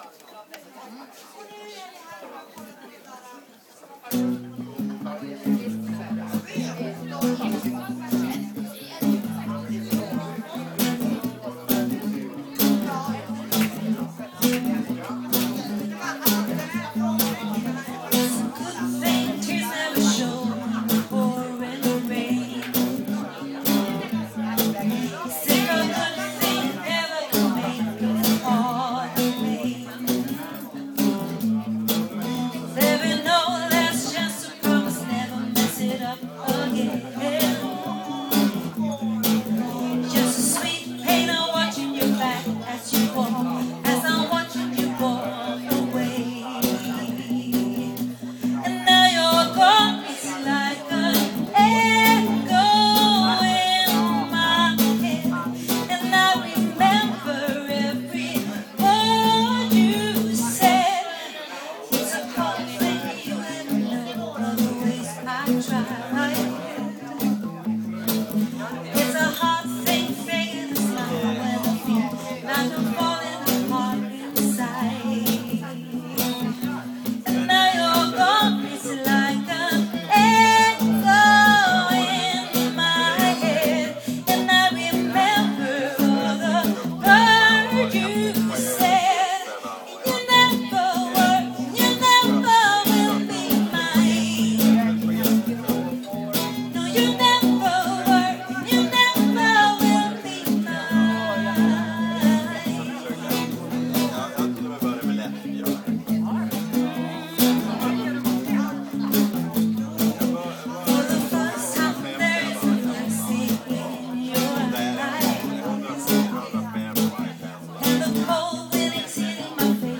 • Trubadur